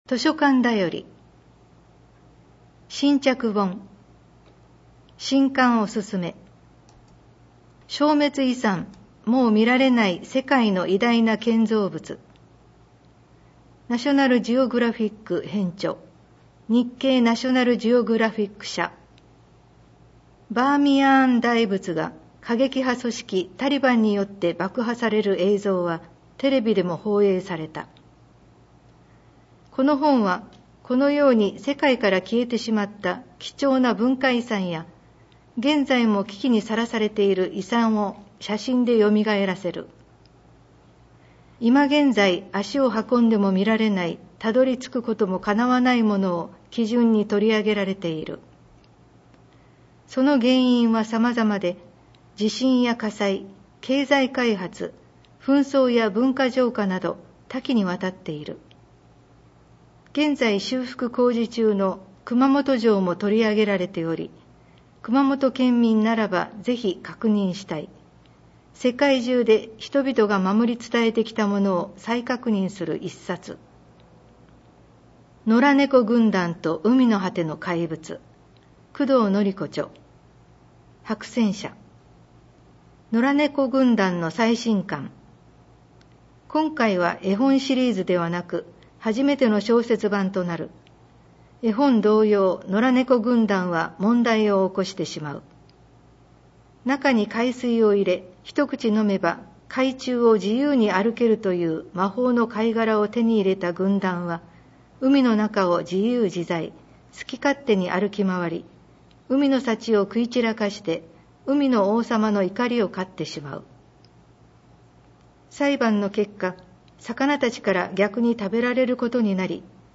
広報こうし平成30年7月号 音訳版